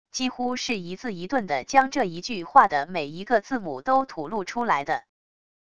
几乎是一字一顿的将这一句话的每一个字母都吐露出来的wav音频